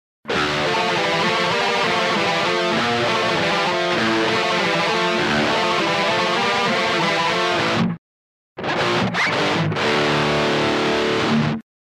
���� ����� - guitar.demo - ����� ����������